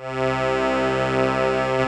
C2 ACCORDI-R.wav